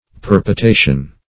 Search Result for " perpotation" : The Collaborative International Dictionary of English v.0.48: Perpotation \Per`po*ta"tion\, n. [L. perpotatio, fr. perpotate.
perpotation.mp3